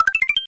smw_1up.wav